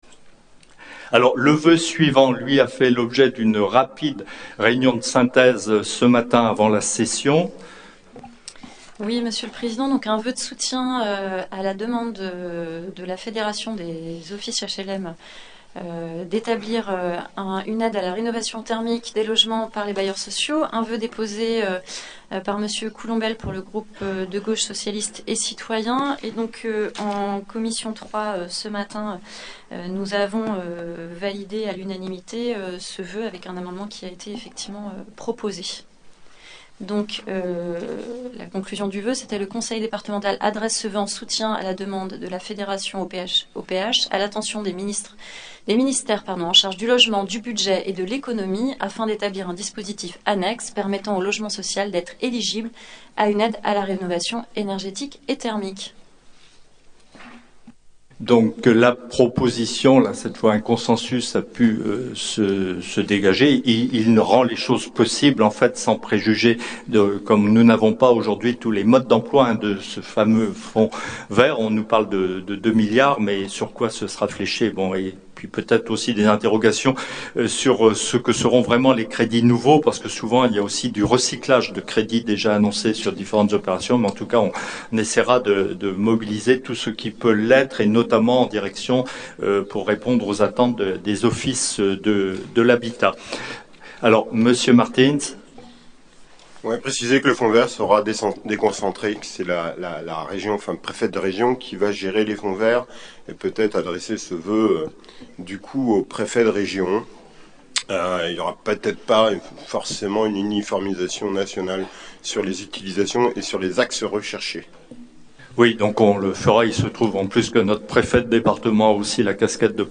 • Assemblée départementale du 15/12/22